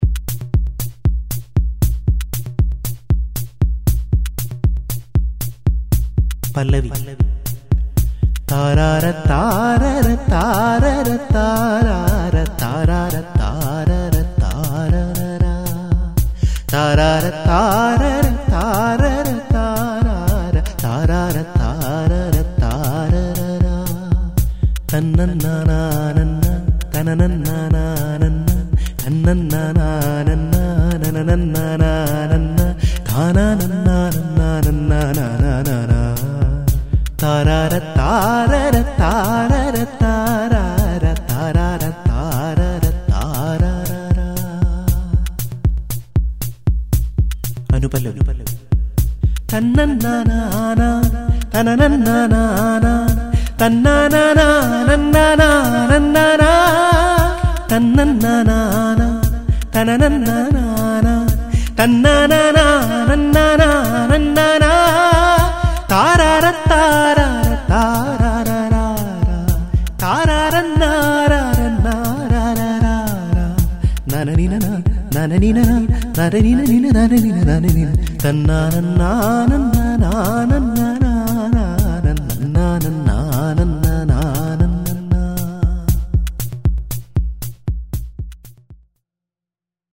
ഈ ഈണം നിങ്ങൾക്കായി ചിട്ടപ്പെടുത്തി മൂളിയിരിക്കുന്നത്